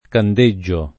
candeggio [ kand %JJ o ] s. m.; pl. ‑gi